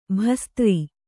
♪ bhastri